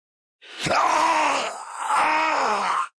Dark Souls/Bloodborne death SFX, for the meme.